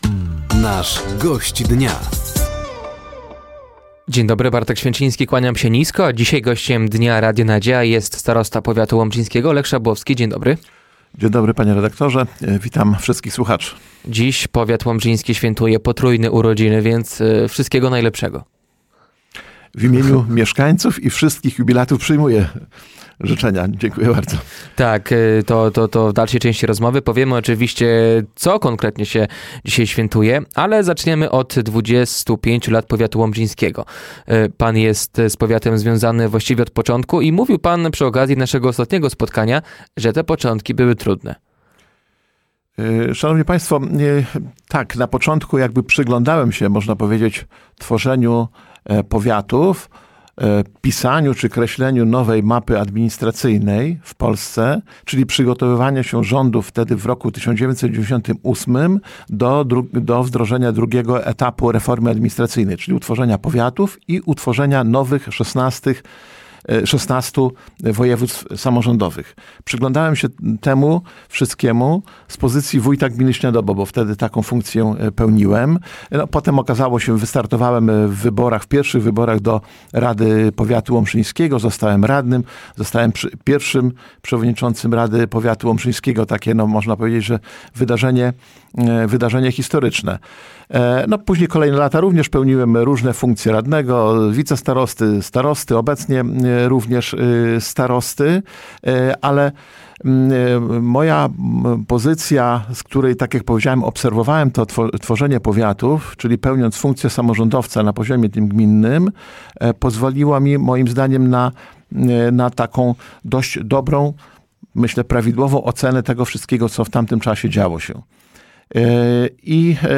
Gościem Dnia Radia Nadzieja był starosta powiatu łomżyńskiego Lech Szabłowski. Tematem rozmowy było 25-lecie powiatu.